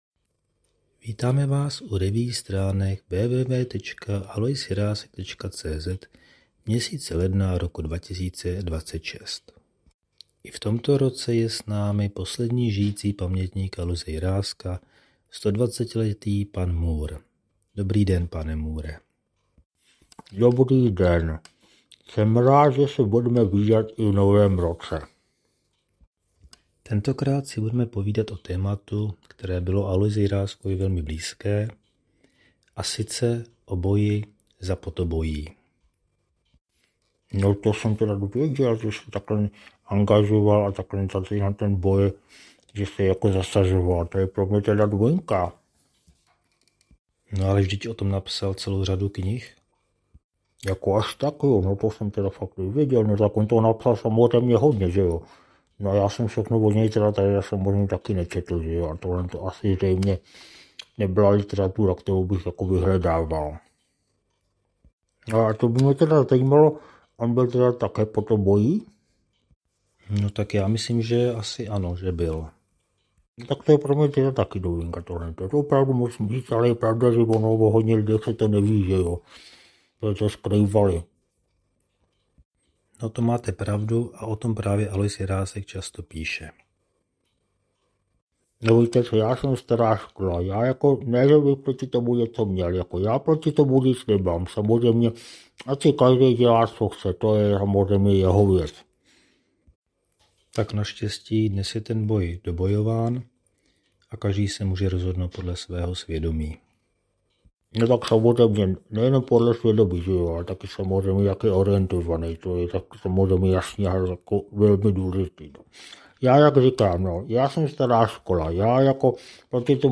První letošní amatérský pokus o zvukovou revue se s obvyklou humornou nadsázkou zabývá problematikou podobojí, kterému věnoval Alois Jirásek celou řadu svých děl a za které se kdysi v Čechách bojovalo a umíralo.